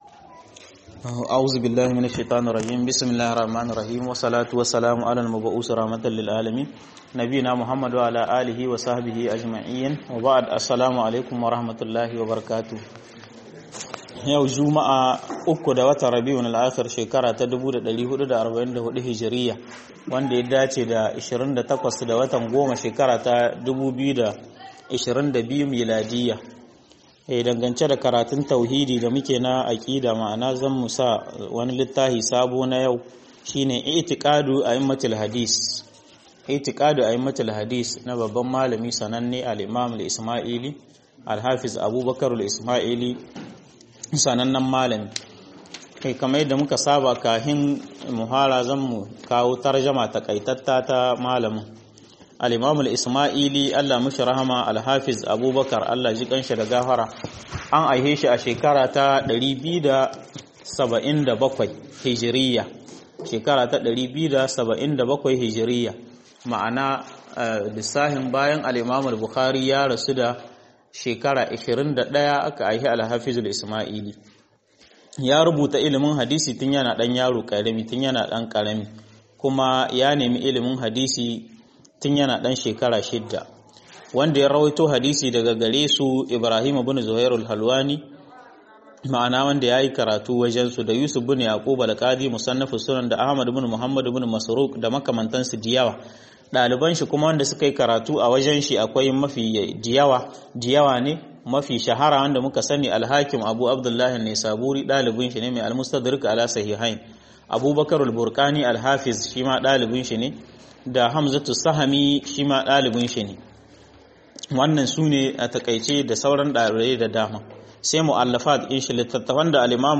1- اعتقاد اهل الحديث - MUHADARA